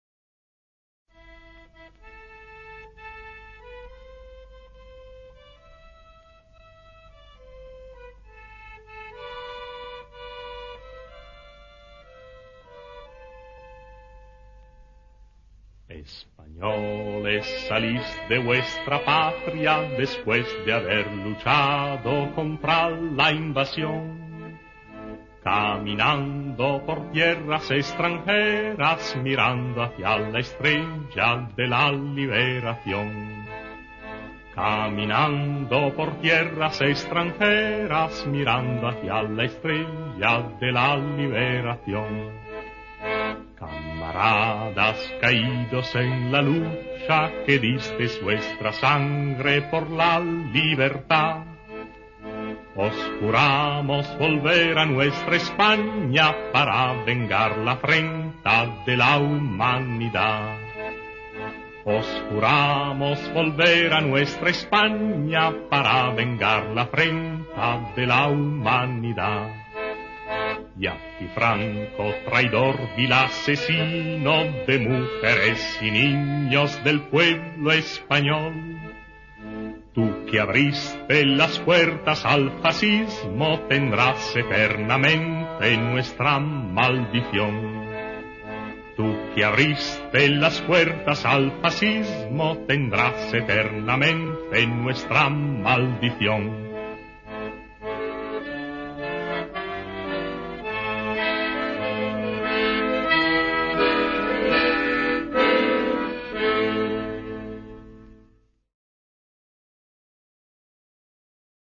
Испанская песня времён гражданской войны
Исполняет просто дивный баритон